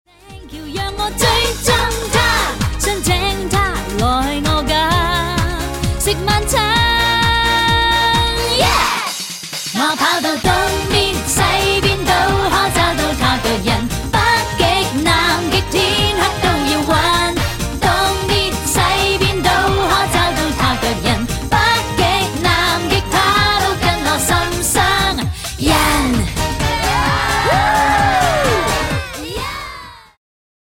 充滿動感和時代感
有伴奏音樂版本